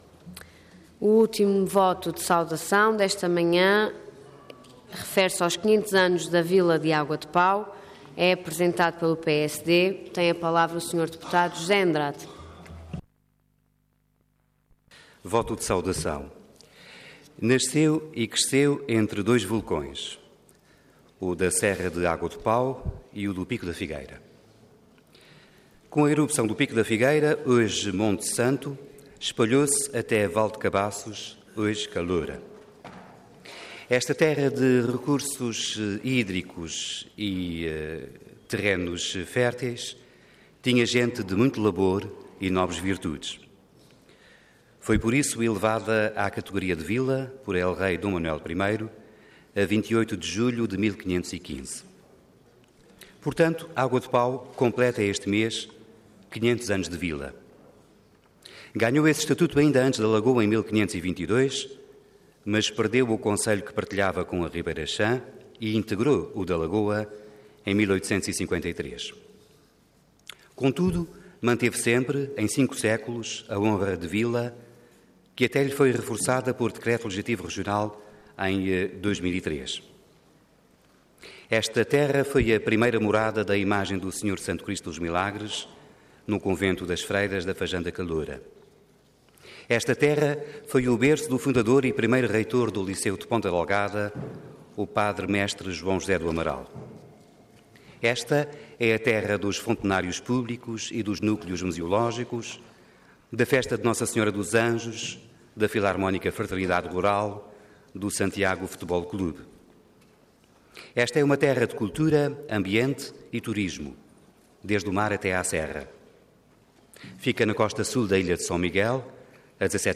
Detalhe de vídeo 9 de julho de 2015 Download áudio Download vídeo Processo X Legislatura 500 Anos da Vila de Água de Pau Intervenção Voto de Saudação Orador José Andrade Cargo Deputado Entidade PSD